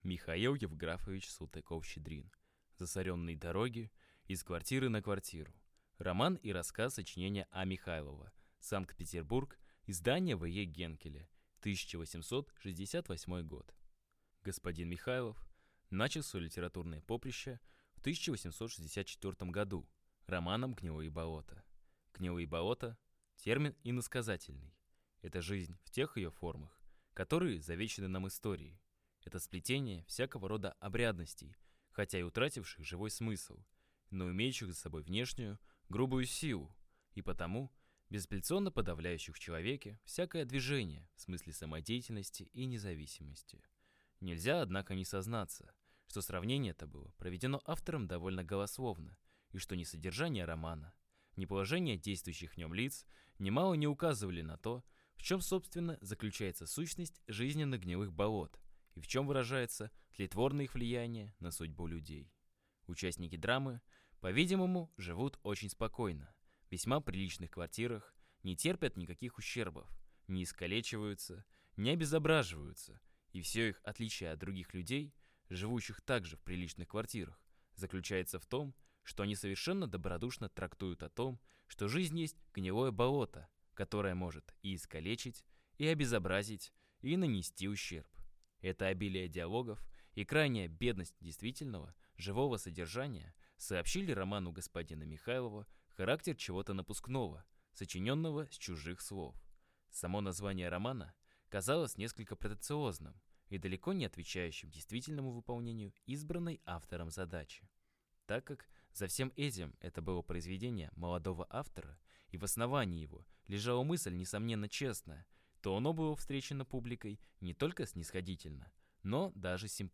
Аудиокнига Засоренные дороги и с квартиры на квартиру | Библиотека аудиокниг